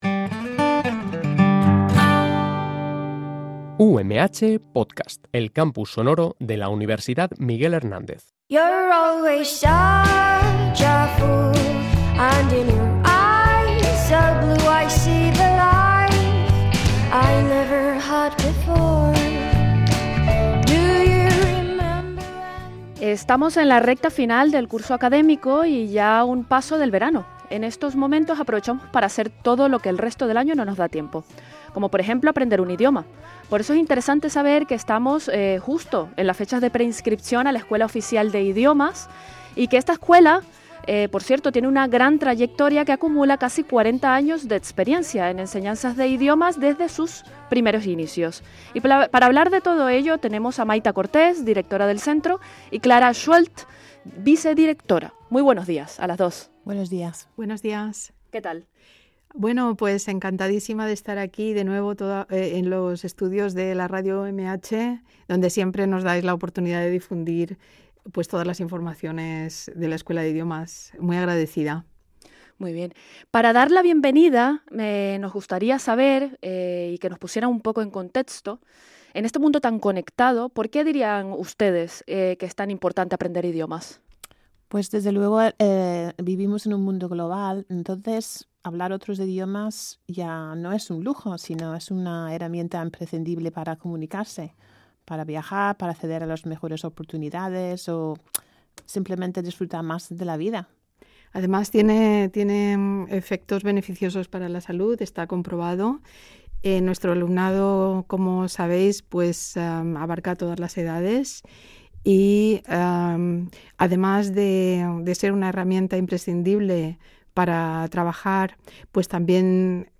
Radio UMH dedica un programa especial a la Escuela Oficial de Idiomas de Elche, 16 de junio de 2025